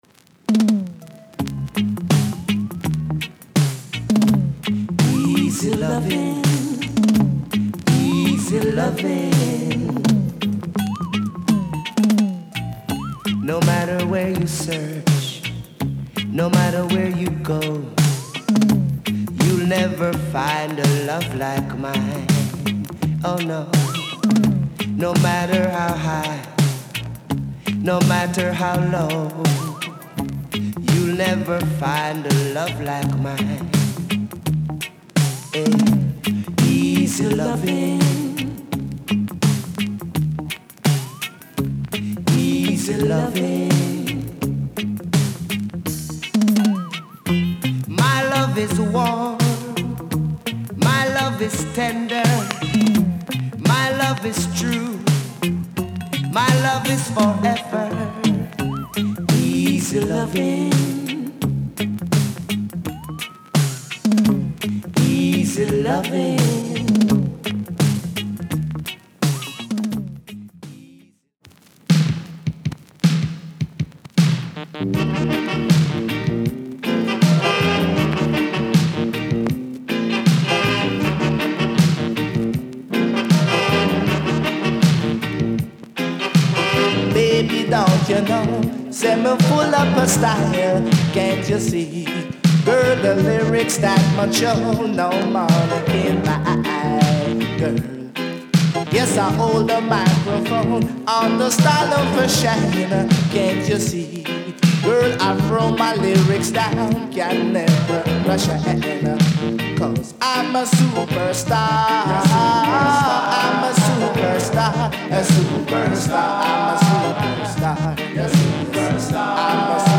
Lovers, Reggae, Roots レア 45's
(本盤からの録音・続けて試聴できます)
ラヴァーズ・ライクなルーツなリズムともにレイジーでベテランの味わいたっぷりで魅了する